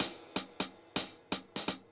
快速时髦的爵士鼓 帽子和符号 125 Bpm
Tag: 125 bpm Jazz Loops Drum Loops 330.92 KB wav Key : Unknown